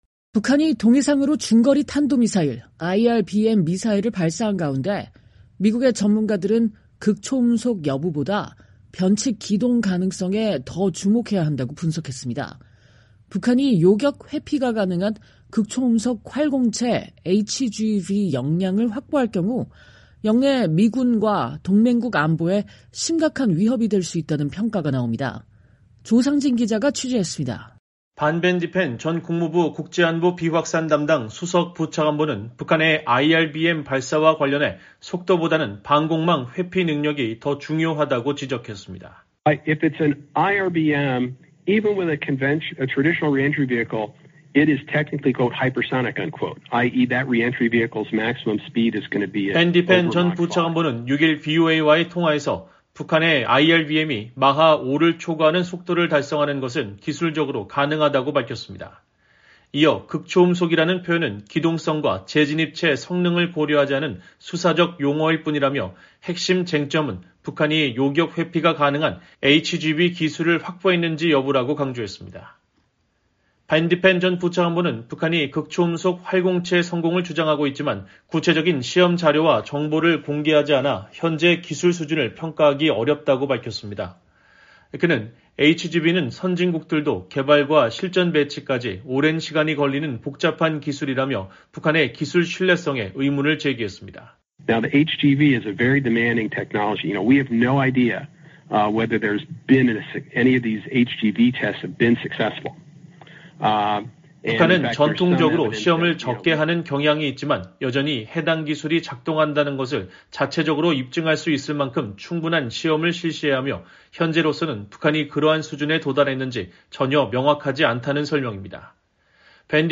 밴 디펜 전 부차관보는 6일 VOA와의 통화에서 북한의 IRBM이 마하 5를 초과하는 속도를 달성하는 것은 기술적으로 가능하다고 밝혔습니다.